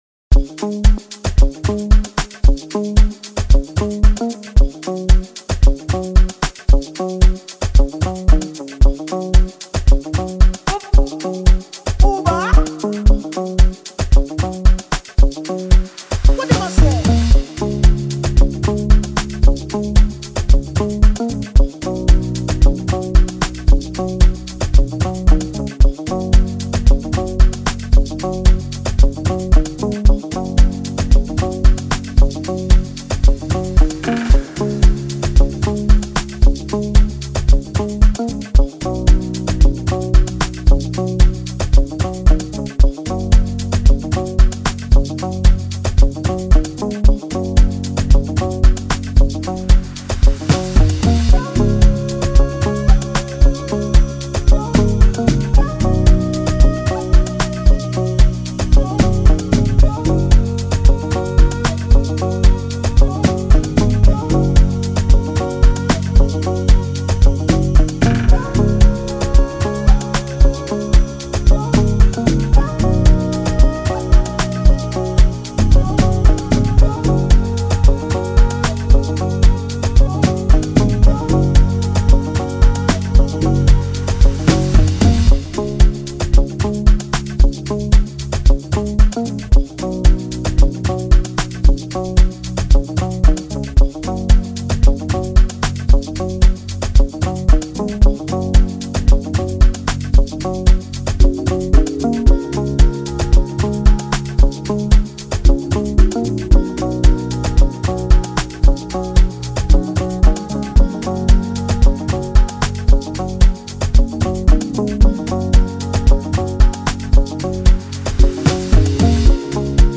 2025 in Dancehall/Afrobeats Instrumentals